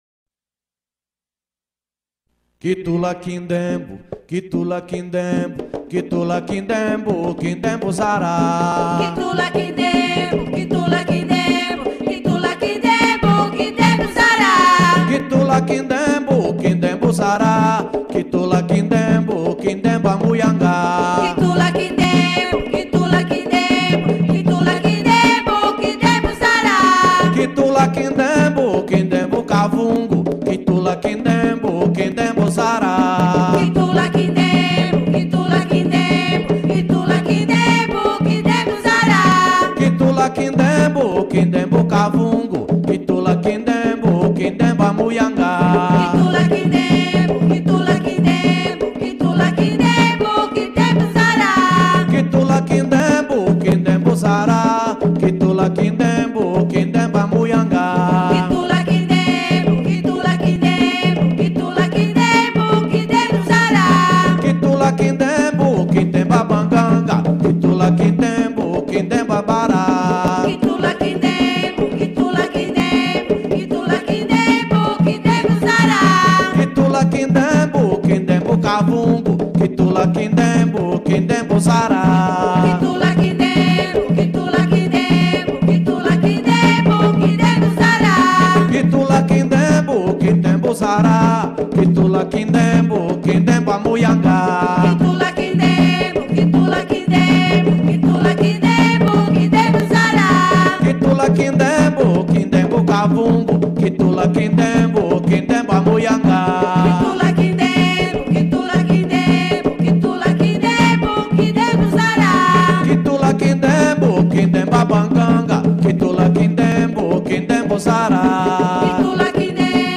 CANTIGAS SAGRADAS DO NKISE TEMPO (KITEMBO).